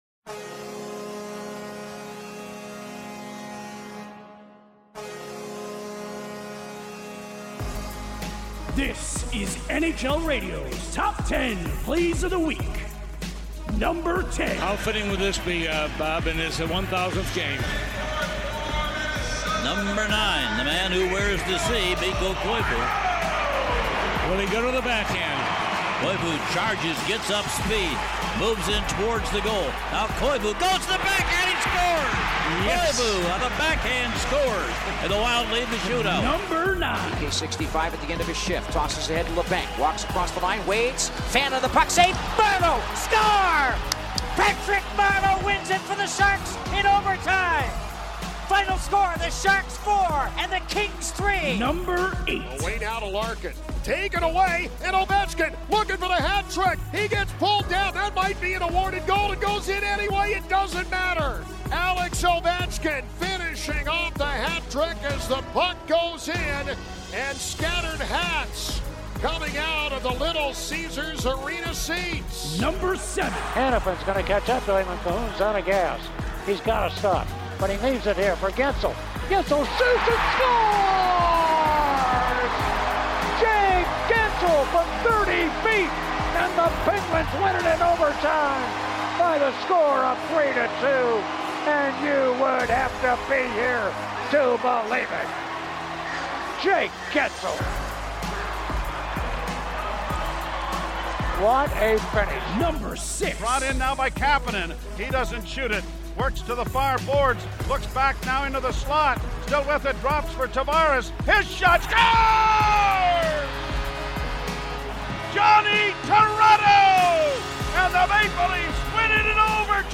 Week ending Sunday, December 1: Your source for the top radio calls, plays and highlights from around the National Hockey League! The countdown begins…now!